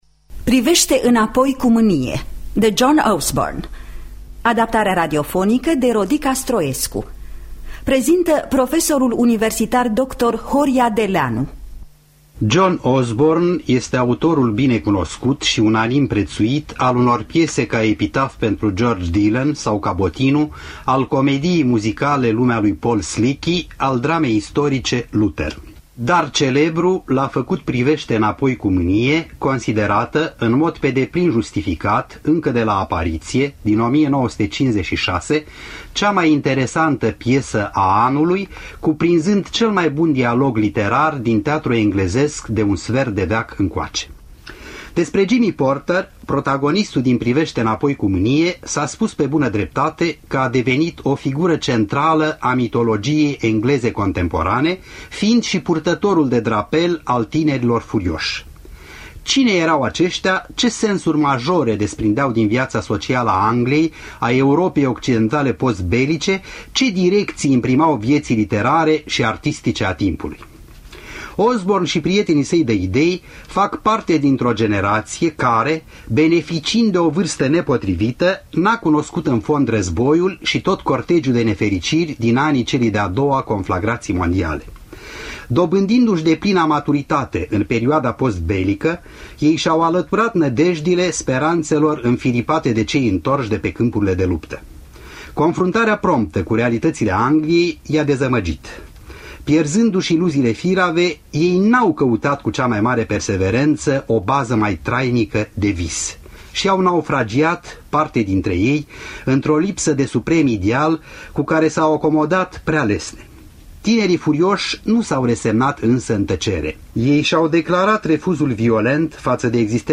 Adaptare radifonică
Înregistrare din anul 1983.